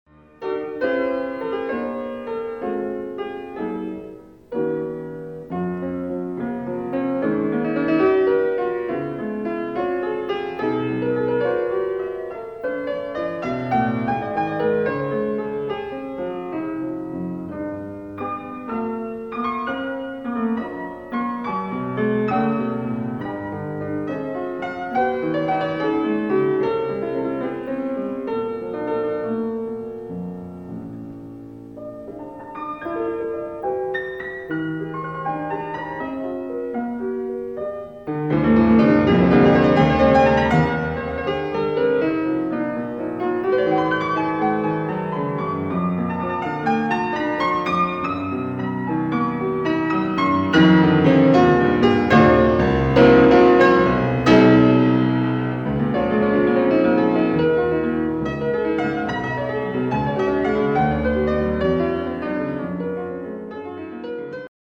Allegro Vivo